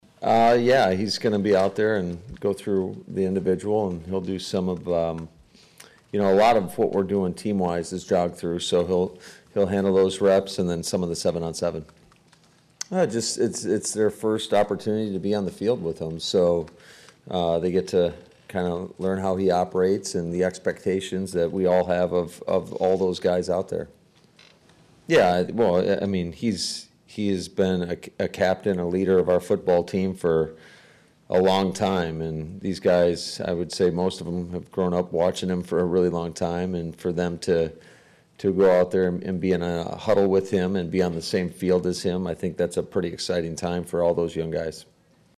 I asked LaFleur what the on-field plan is for the league’s MVP this week and he also discussed the get acquainted time with his new playmaking cast and just the importance of having the team’s most important player here: